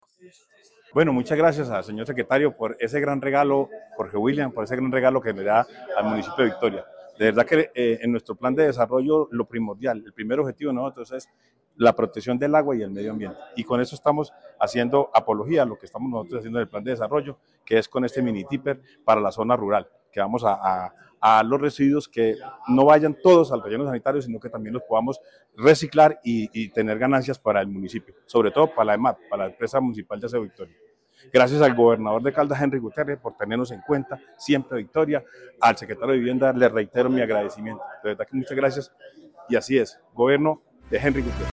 Alcalde de Victoria, Juan Alberto Vargas Osorio.